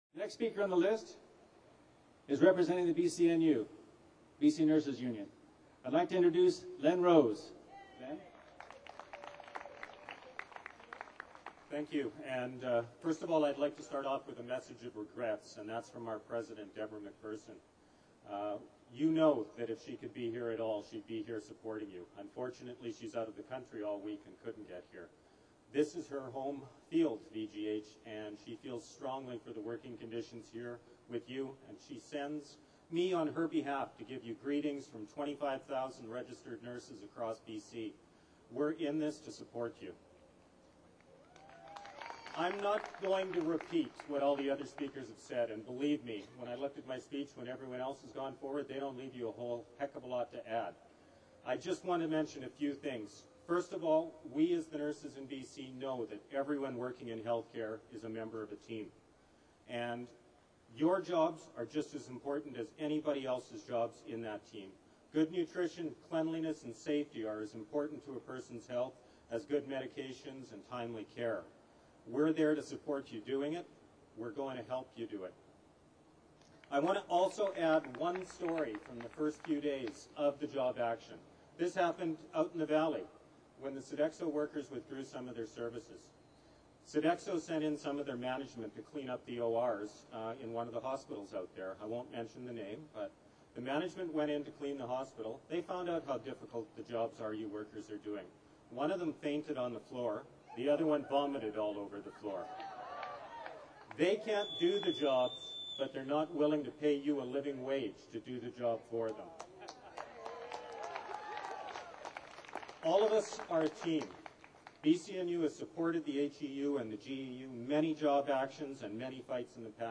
Hospital Employees' Union Sodexho strike rally, 21 September 2005
September 21, 2005 solidarity rally for Sodexho strikers at Vancouver General Hospital, members of the Hospital Employees' Union in British Columbia, Canada.
Labour leaders will speak at a rally in support of 1,100 Sodexho workers who have been engaged in rotating job action since September 15.